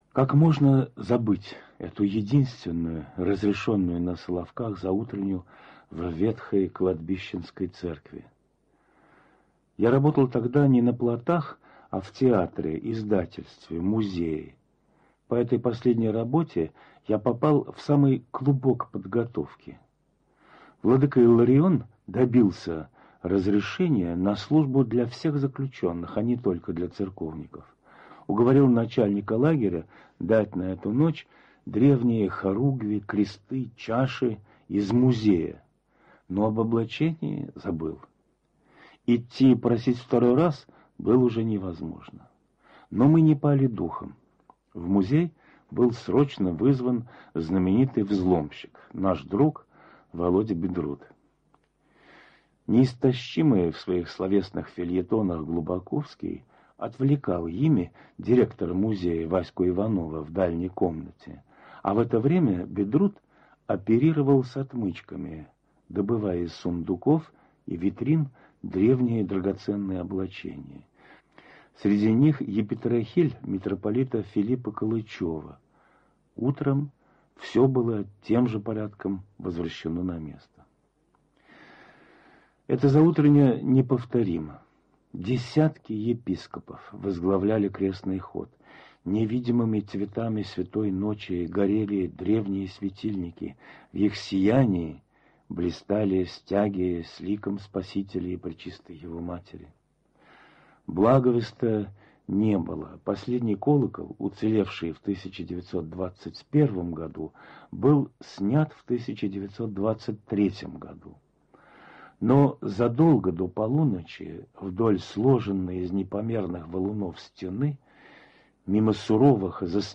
ПАСХА на Соловках, 1925 год. Рассказ о единственной Пасхе, отслуженной на Соловках (в лагере особого назначения) в 1920-е годы. Передача радиостанции "Голос Православия" (Франция), составленная по воспоминаниям Б.Н. Ширяева - бывшего заключенного лагеря.